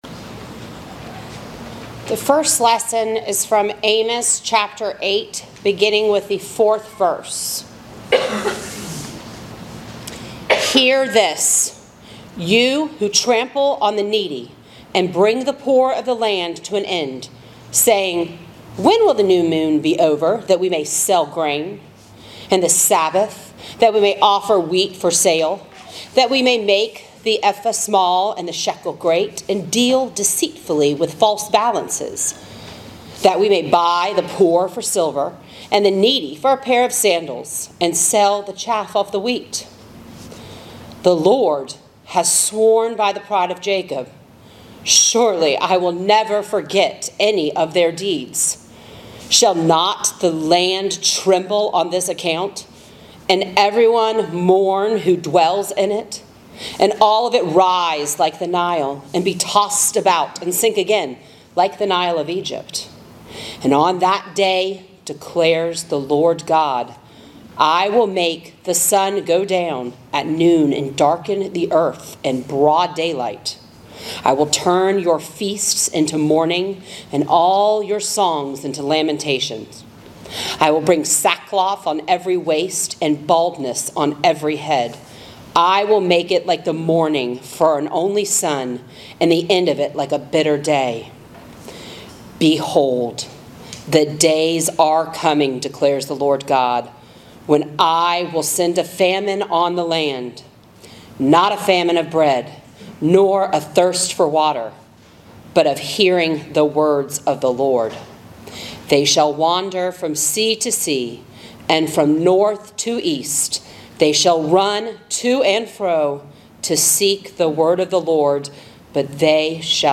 Sermons | St. John's Parish Church